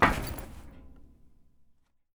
328d67128d Divergent / mods / Soundscape Overhaul / gamedata / sounds / material / human / step / metal_plate3.ogg 57 KiB (Stored with Git LFS) Raw History
metal_plate3.ogg